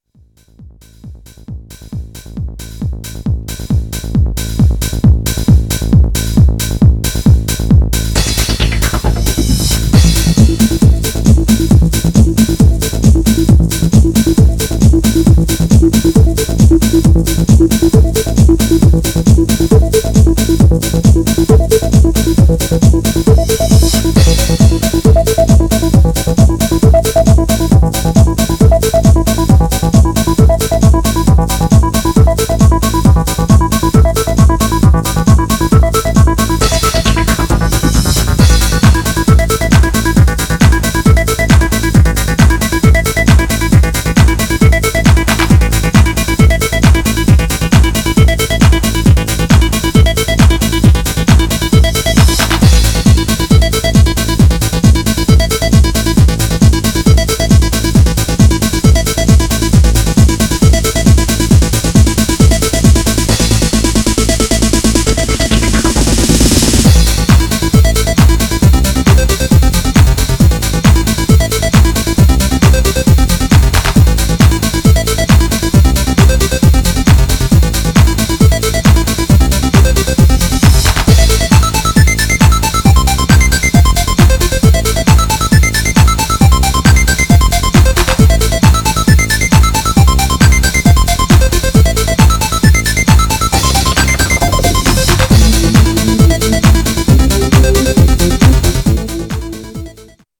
Styl: Progressive, Techno, Trance